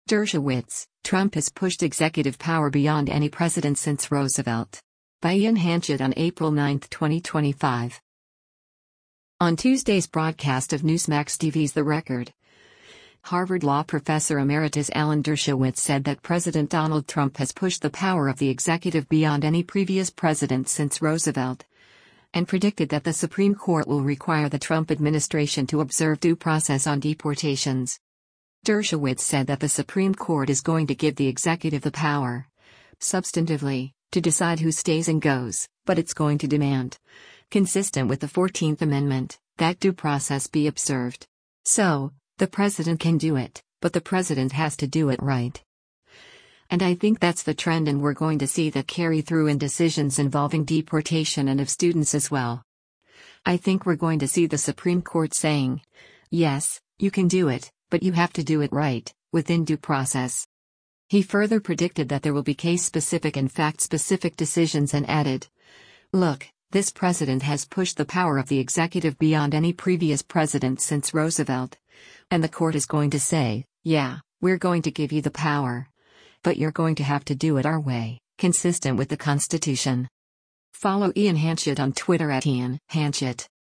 On Tuesday’s broadcast of Newsmax TV’s “The Record,” Harvard Law Professor Emeritus Alan Dershowitz said that President Donald Trump “has pushed the power of the executive beyond any previous president since Roosevelt,” and predicted that the Supreme Court will require the Trump administration to observe due process on deportations.